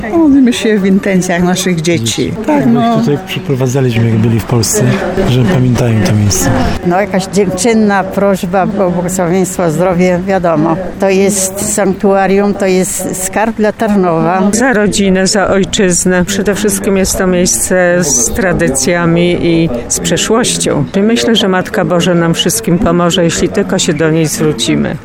– Modlimy się w intencjach naszych dzieci, bo ich tutaj przyprowadzaliśmy jak byli jeszcze w Polsce, żeby pamiętali to miejsce – mówili wierni biorący udział w odpustowej mszy.
To sanktuarium jest skarbem dla Tarnowa – dodawała inna uczestniczka nabożeństwa.